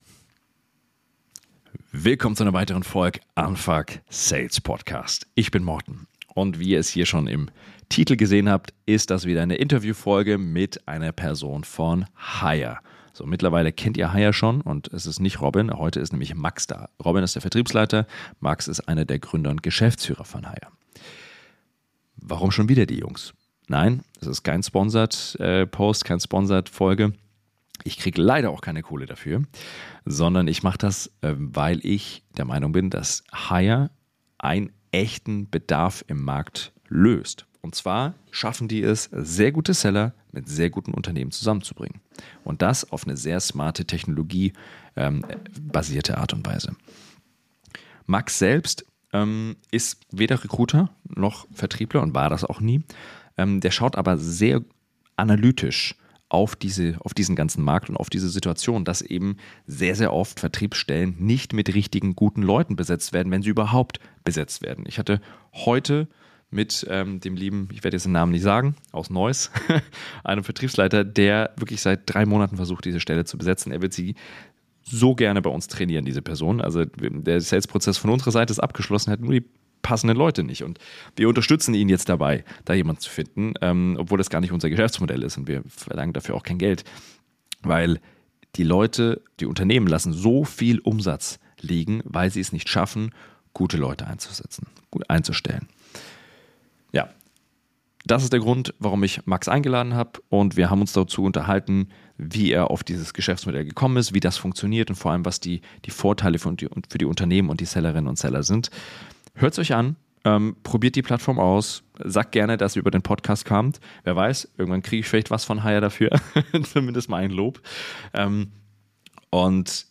So funktioniert der Markt für Sales Talente - Interview